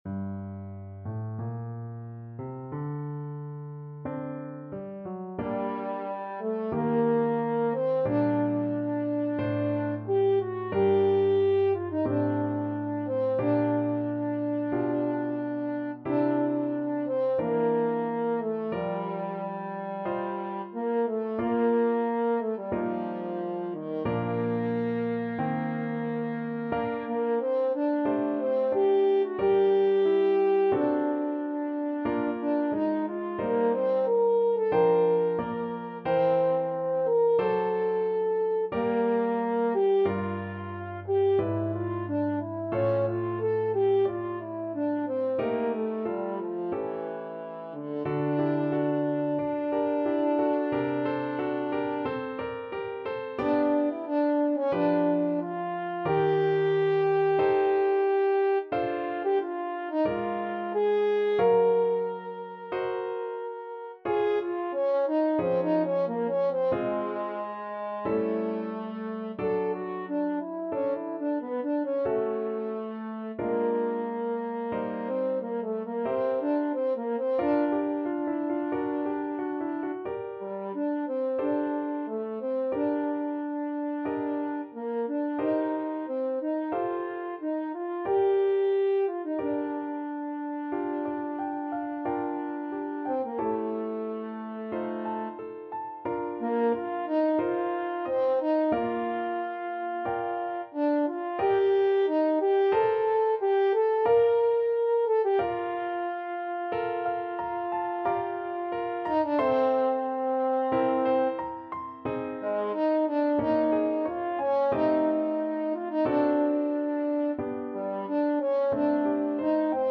Classical Fauré, Gabriel Piece (Vocalise-Etude) French Horn version
G minor (Sounding Pitch) D minor (French Horn in F) (View more G minor Music for French Horn )
4/4 (View more 4/4 Music)
Adagio, molto tranquillo (=60) =45
Classical (View more Classical French Horn Music)